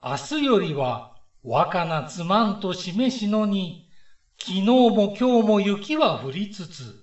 この解説サイトは電子書籍にリンクが貼られていますが、電子書籍をダウンロードせずに読まれる方（主にスマートフォンで読まれる方）のために、電子書籍の表紙とページの画像、語句解説、朗読音声などが含まれています。